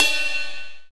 RX RIDE BELL.wav